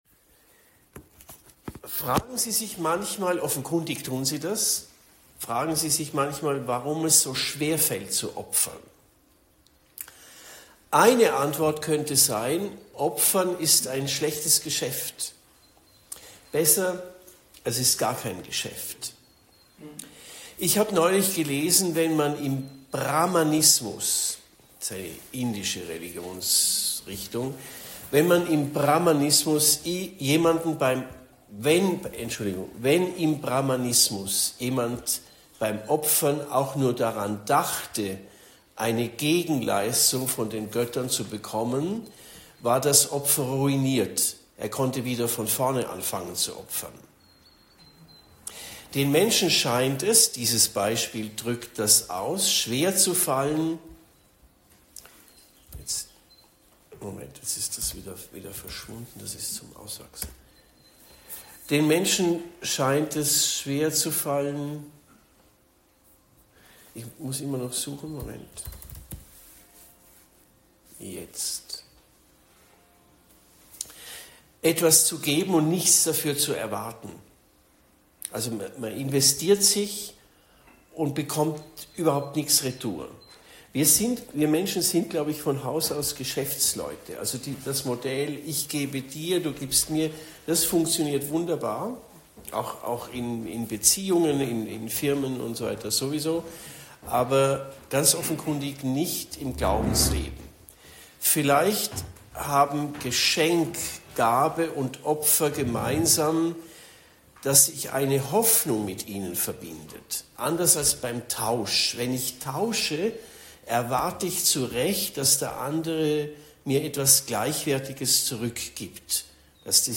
Vortrag bei den Ordensexerzitien in Stift Schlägl, 07. bis 09. März 2025